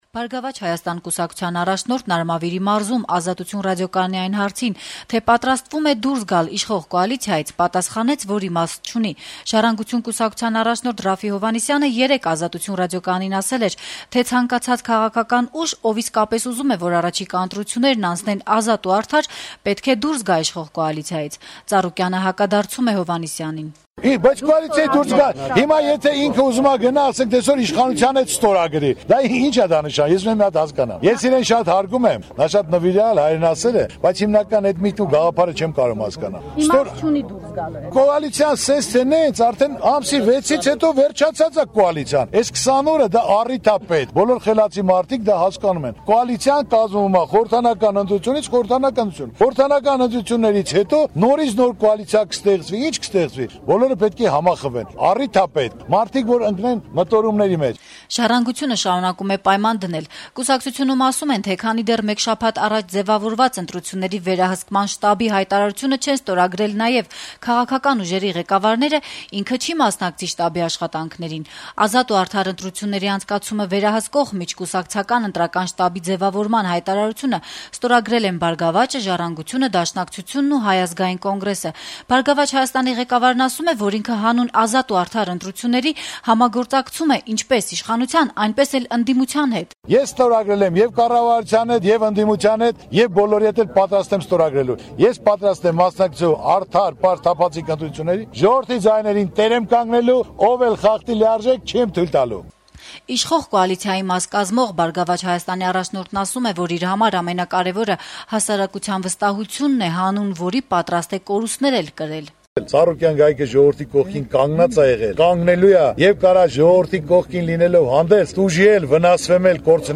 «Բարգավաճ Հայաստան» կուսակցության առաջնորդն Արմավիրի մարզում պատասխանեց «Ազատություն» ռադիոկայանի հարցին, թե դուրս կգա՞ իշխող կոալիցիայից: